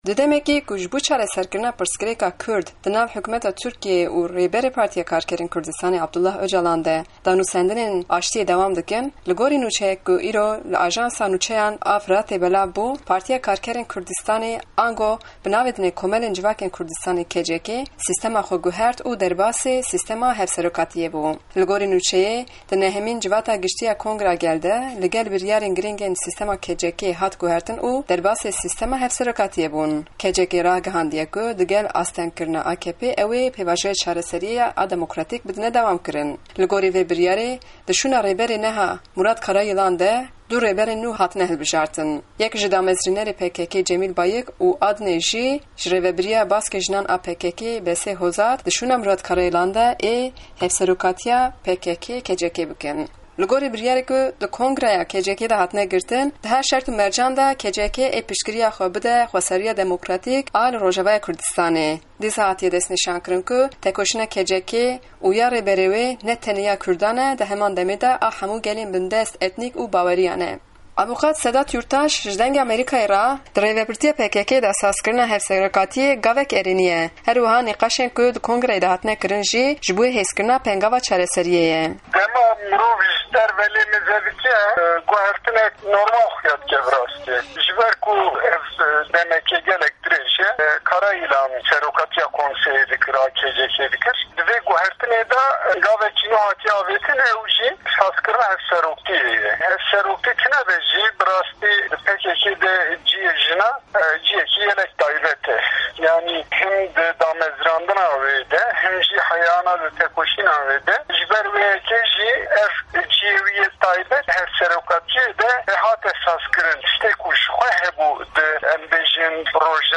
Raporta Taybet ya ser Guherina Rêbertîya PKK'ê